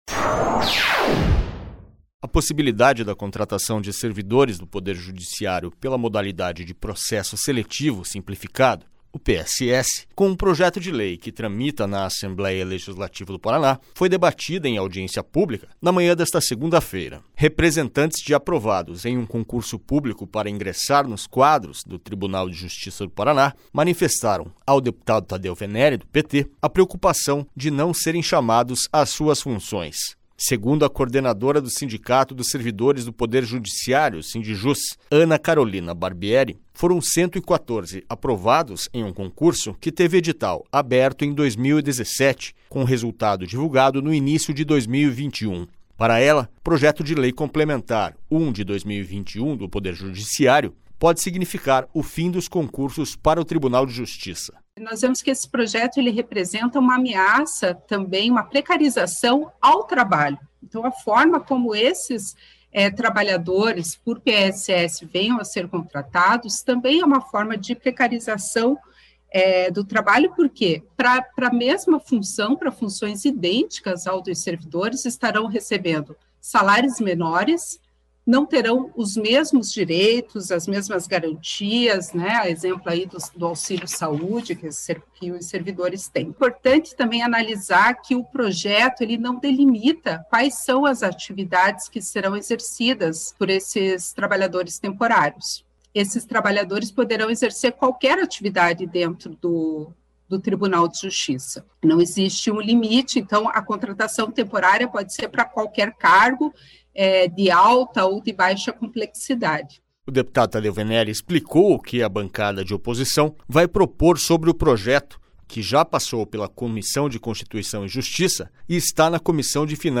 SONORA TADEU VENERI